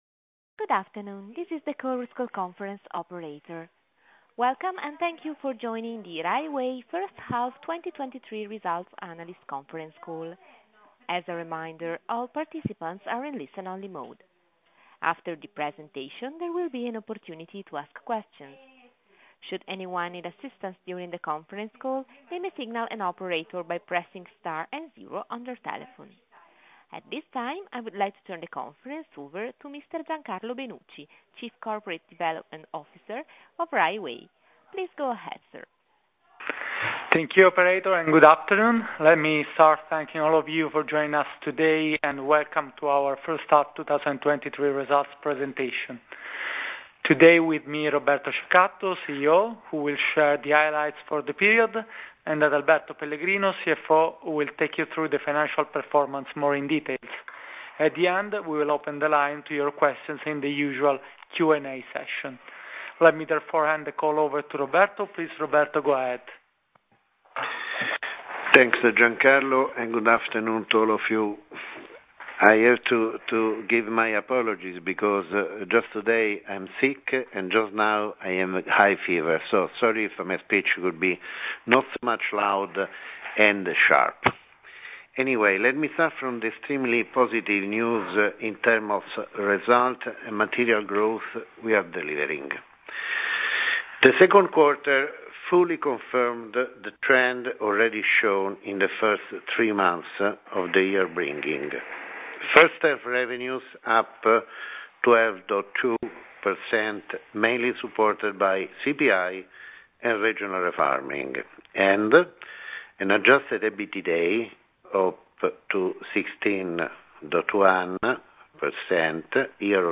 Audio mp3 Conference call Risultati 1H2023.mp3